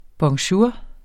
Udtale [ bʌŋˈɕuːɐ̯ ]